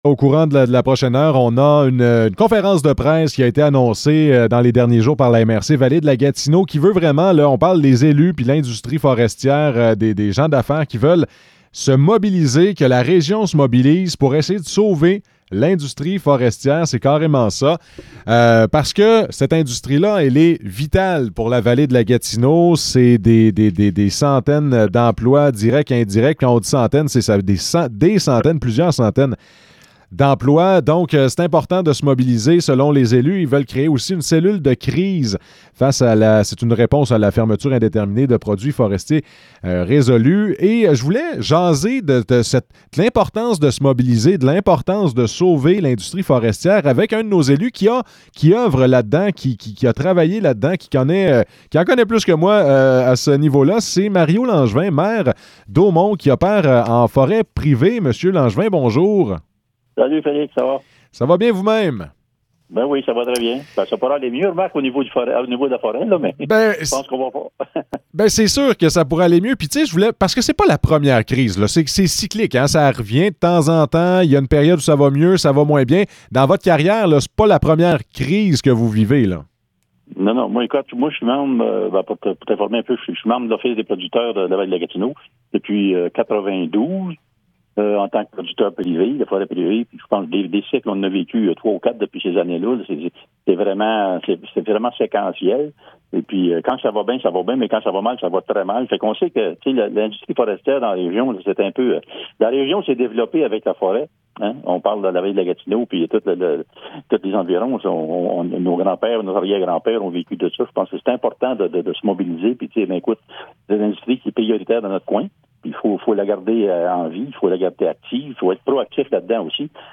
Entrevue avec Mario Langevin